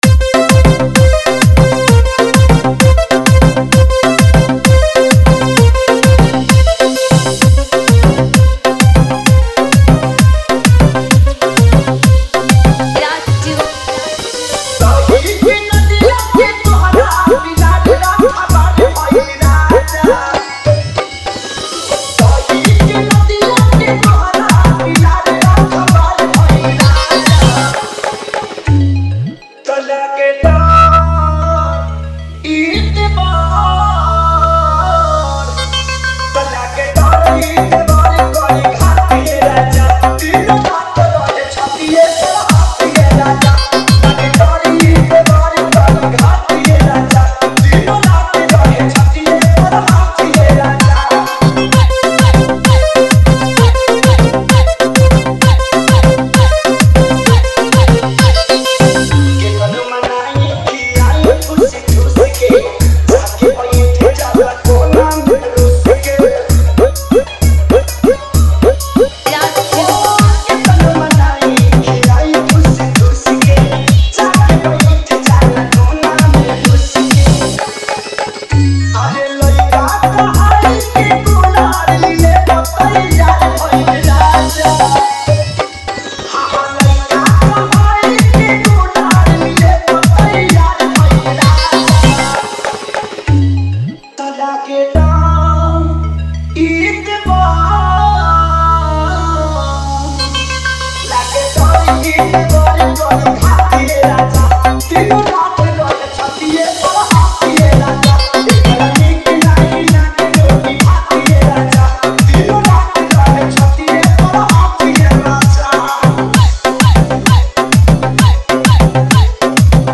Upcoming: - 2025 Special Bhojpuri EDM Tahalka Song